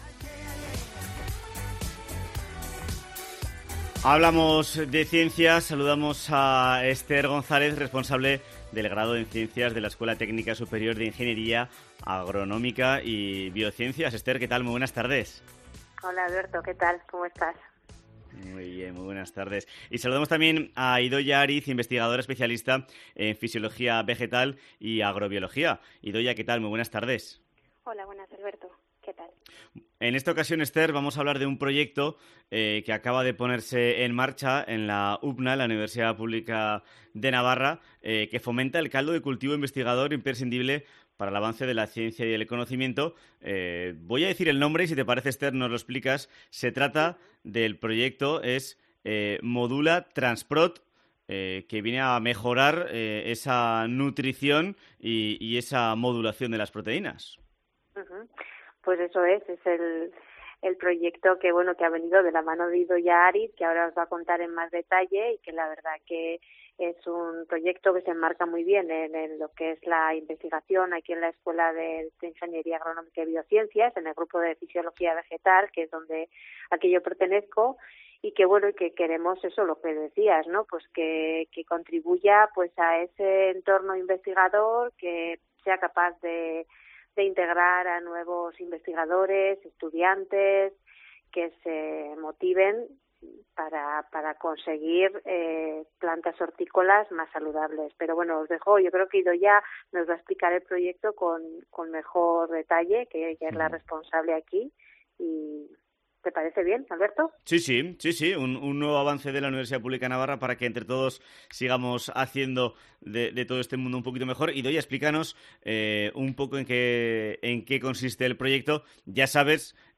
Entrevista en COPE Navarra sobre ciencia e investigación.